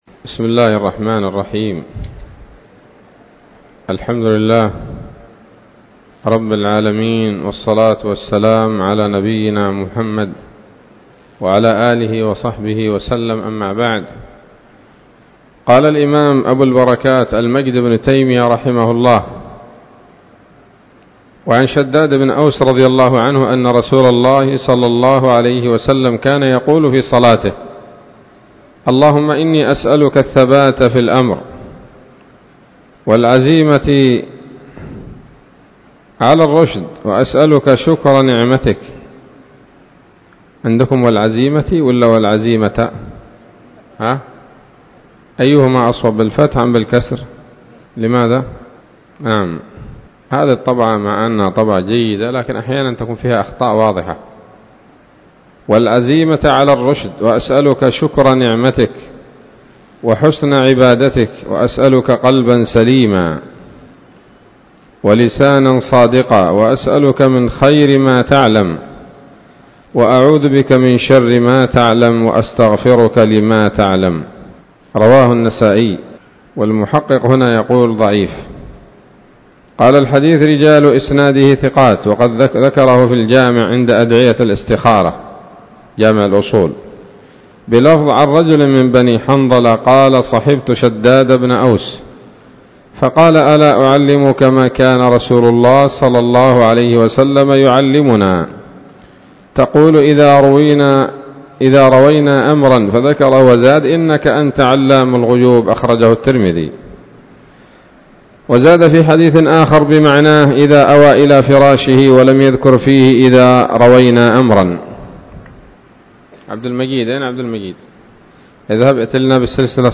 الدرس السادس والثمانون من أبواب صفة الصلاة من نيل الأوطار